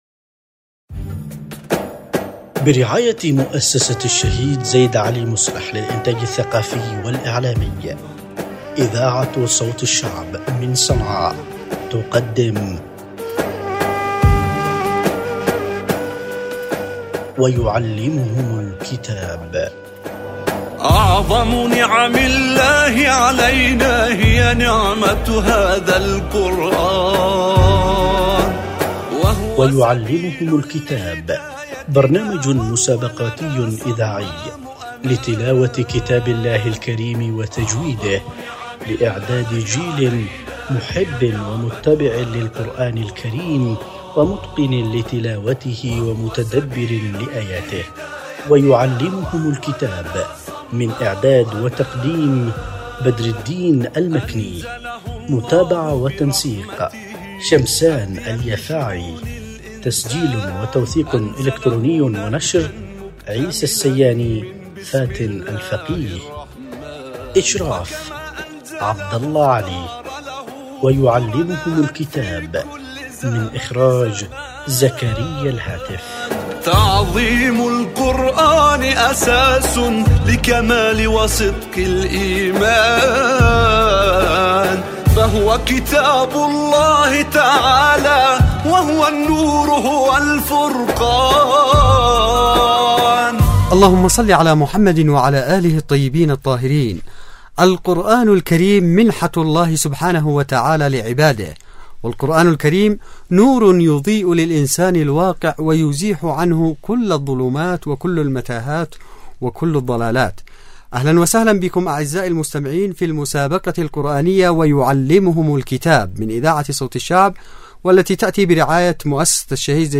مسابقة القرآن الكريم (ويعلمهم الكتاب) ح 4